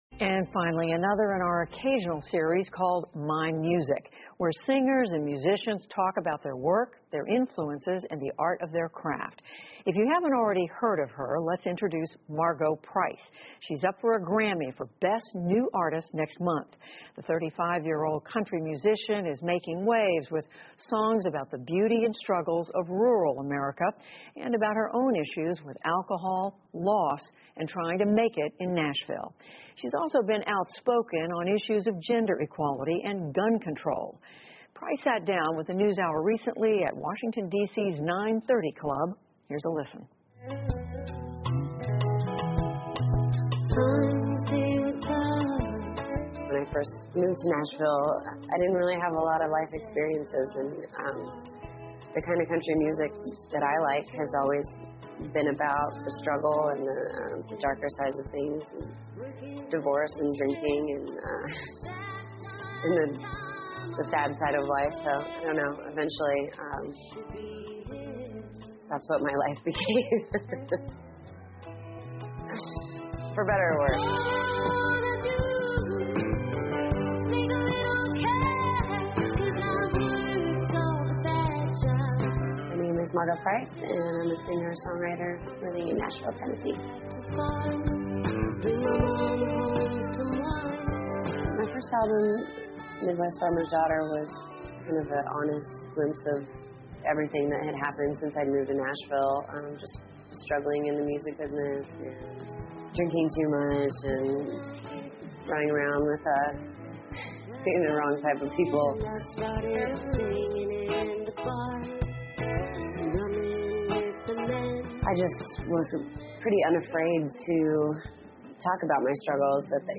PBS高端访谈:了解一下马戈·普莱斯的音乐 听力文件下载—在线英语听力室